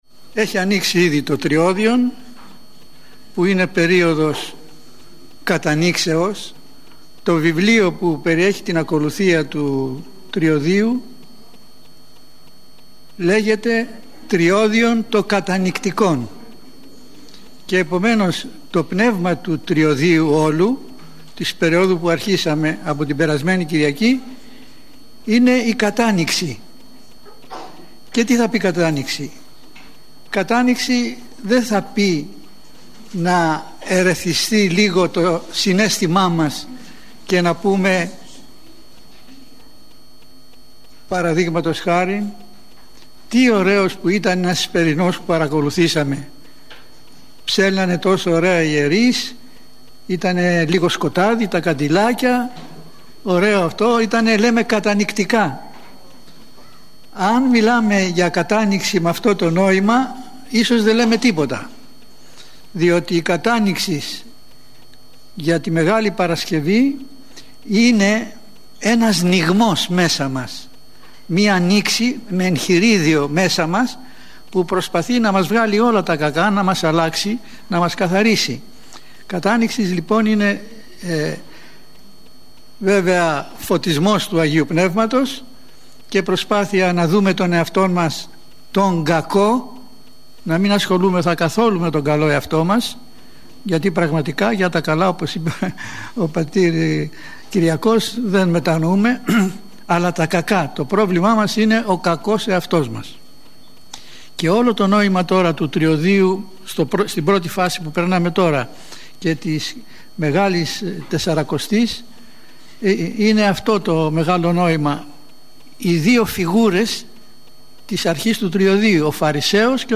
Η ομιλία αυτή “δόθηκε” στα πλαίσια του σεμιναρίου Ορθοδόξου πίστεως – του σεμιναρίου οικοδομής στην Ορθοδοξία. Το σεμινάριο αυτό διοργανώνεται στο πνευματικό κέντρο του Ιερού Ναού της Αγ. Παρασκευής (οδός Αποστόλου Παύλου 10), του ομωνύμου Δήμου της Αττικής.